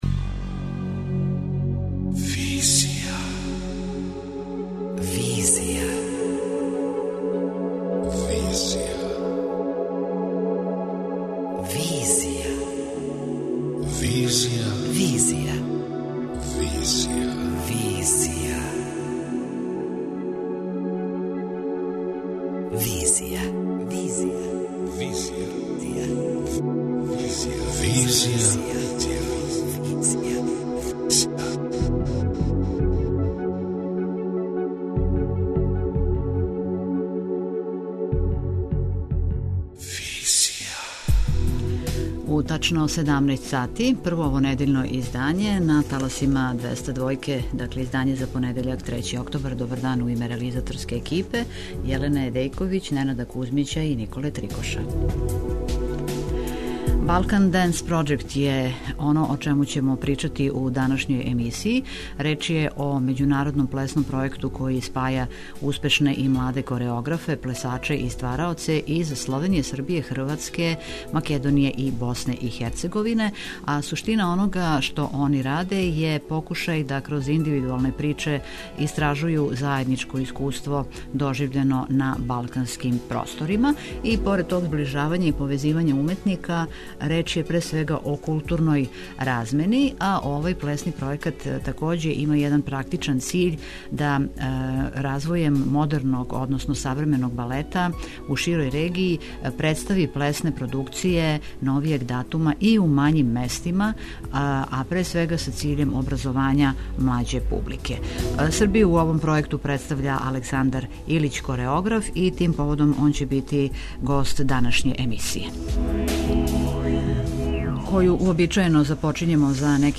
преузми : 27.30 MB Визија Autor: Београд 202 Социо-културолошки магазин, који прати савремене друштвене феномене.